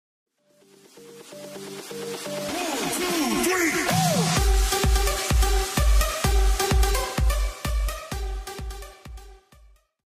twitch-follower-alert-sound-track.mp3